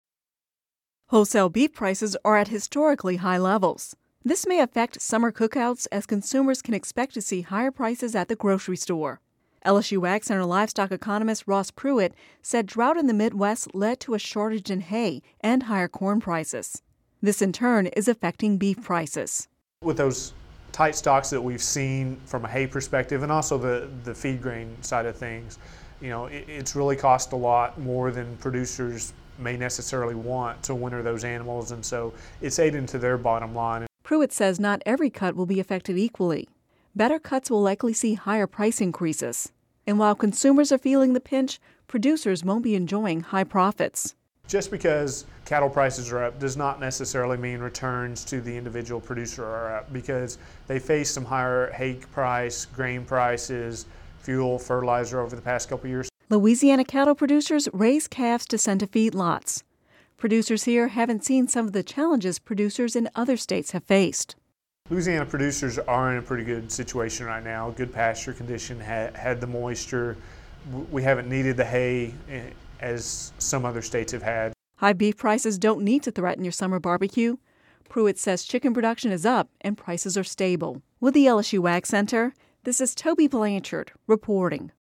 (Audio News 05/17/13) Wholesale beef prices are at historically high levels. This may affect summer cookouts as consumers can expect to see higher prices at the grocery store.